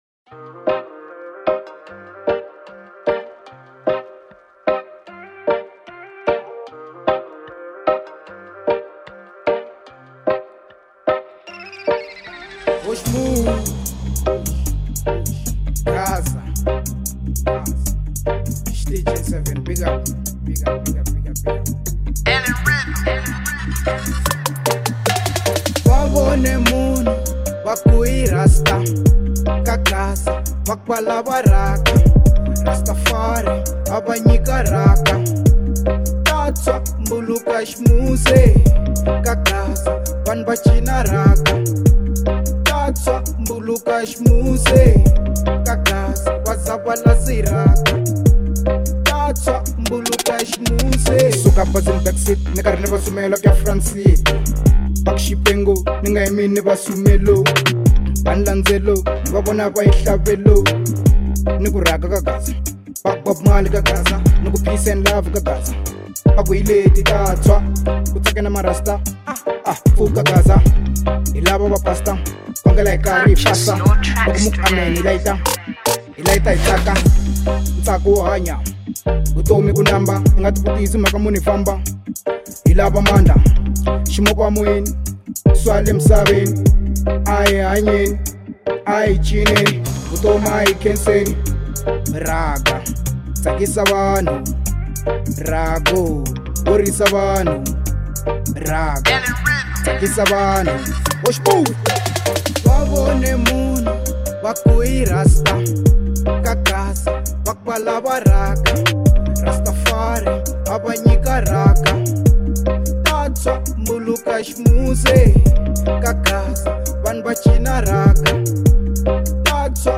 03:26 Genre : Reggae Size